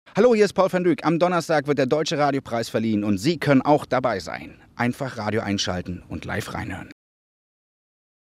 IDs von Paul van Dyk